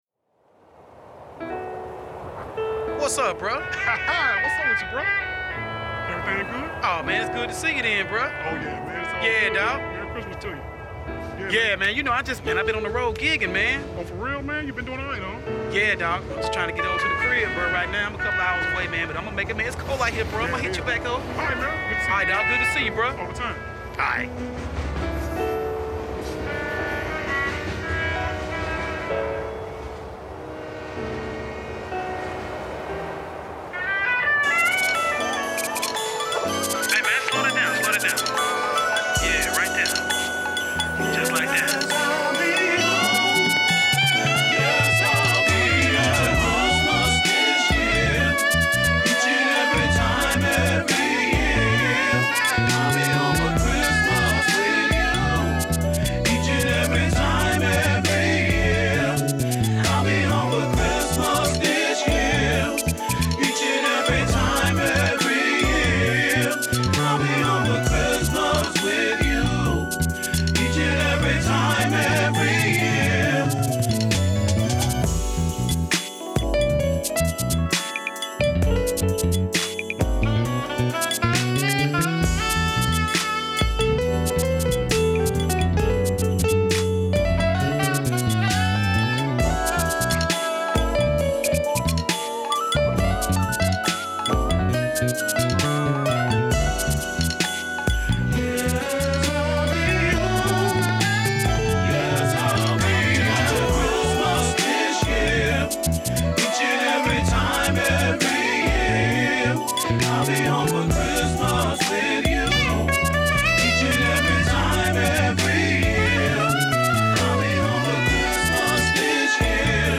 Jazz Artist